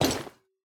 Equip_copper6.ogg